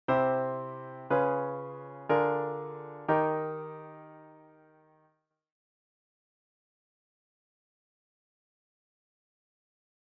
For example, a Db7 can act as part of a progression that changes key from C major to Eb major, like this:
C  Db7  Bb7/D  Eb. That progression uses a chromatically rising bass line.